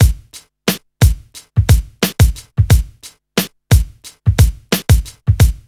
RNB89BEAT2-R.wav